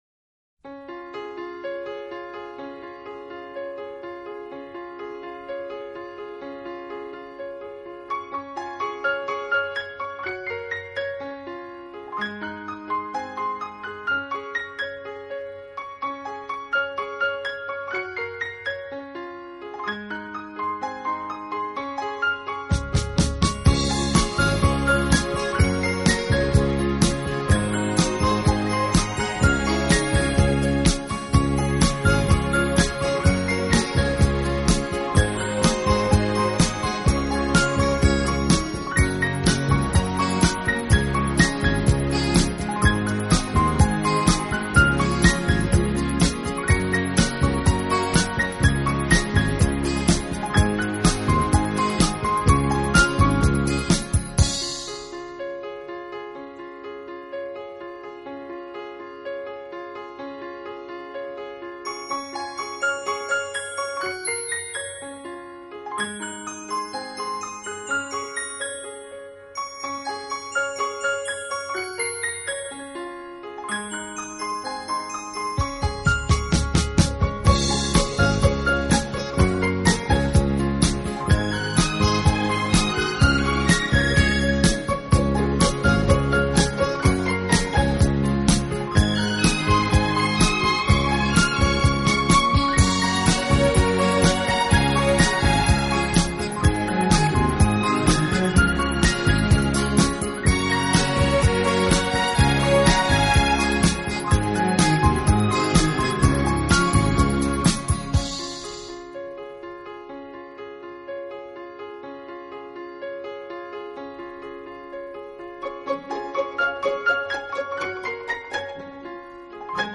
乐队以弦乐为中坚，演奏时音乐的处理细腻流畅，恰似一叶轻舟，随波荡